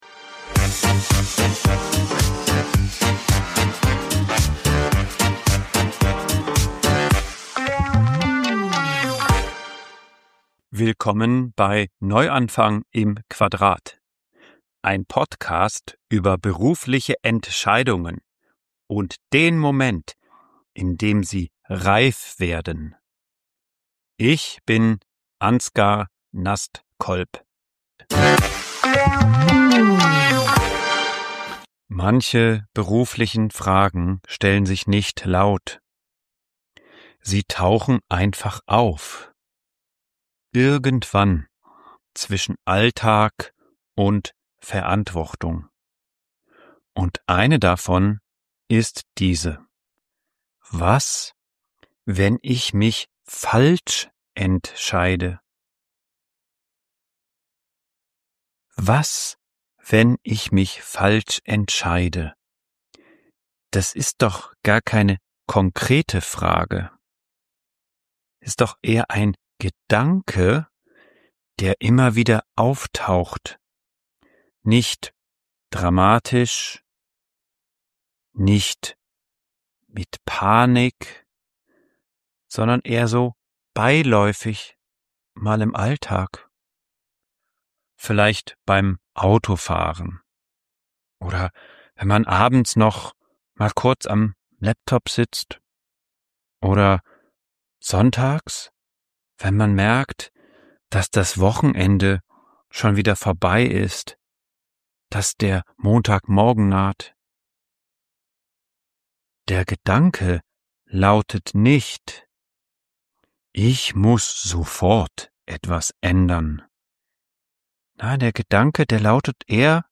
Eine ruhige Folge für Menschen, die spüren, dass eine Entscheidung näher rückt.